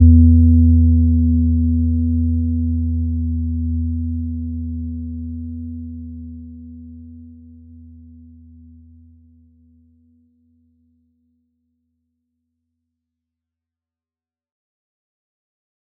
Gentle-Metallic-1-E2-mf.wav